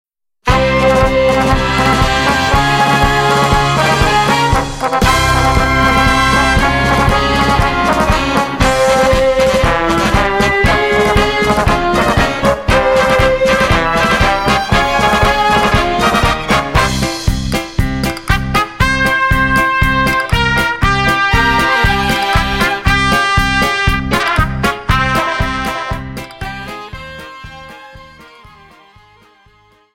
PASO-DOBLE:59(123)